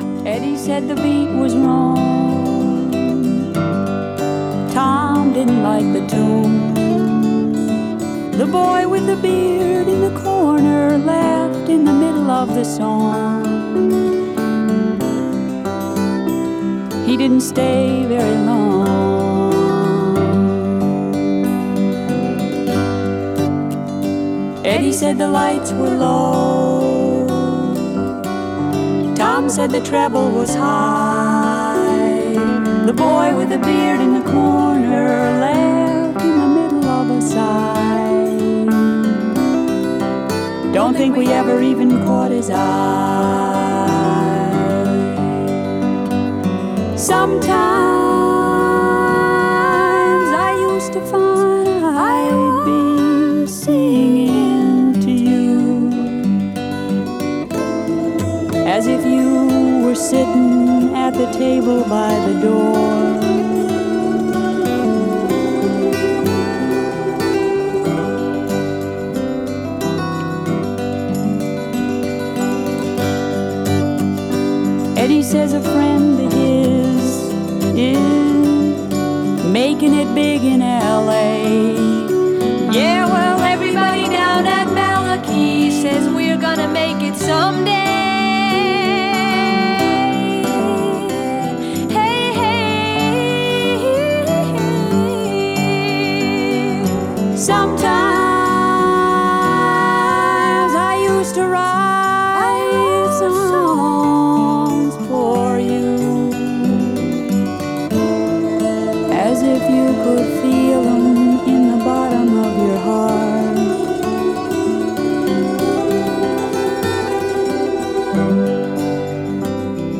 (captured from webcast)
album version